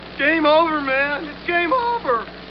gameover.mp3